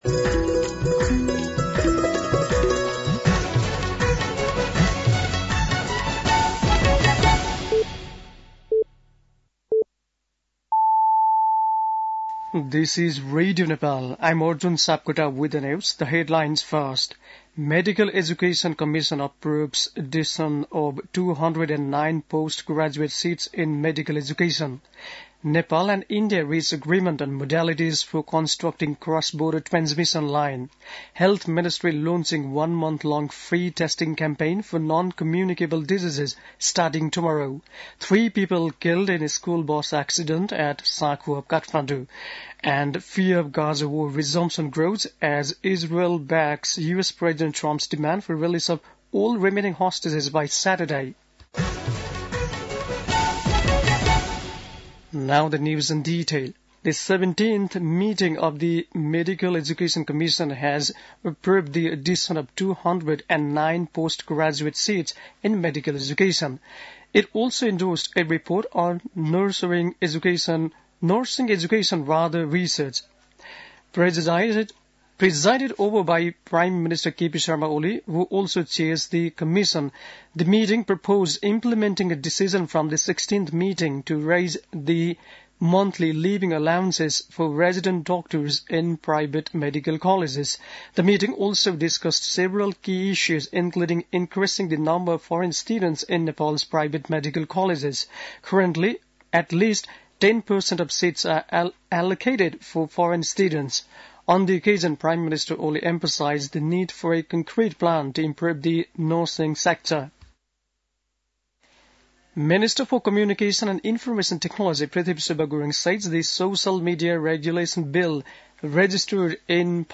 बेलुकी ८ बजेको अङ्ग्रेजी समाचार : १ फागुन , २०८१